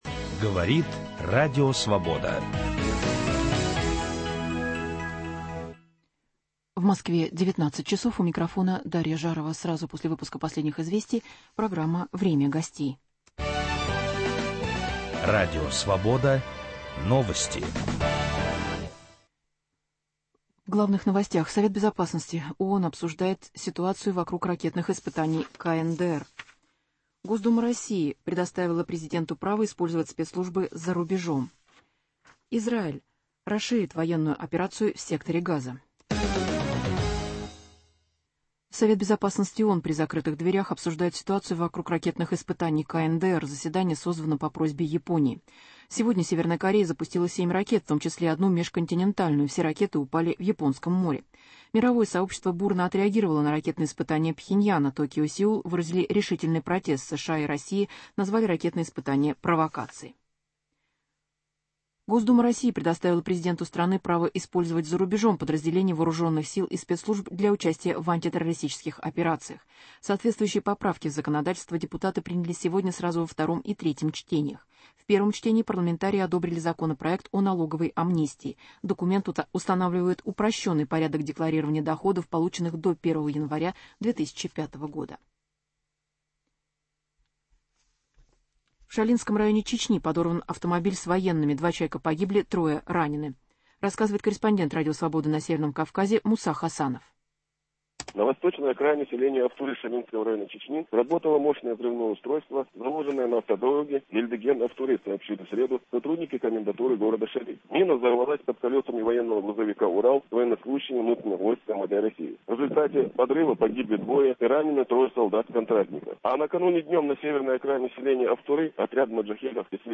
Сохранится ли в России независимая от государства муниципальная власть? Наш гость - председатель Комитета по местному самоуправлению Государственной Думы России Владимир Мокрый.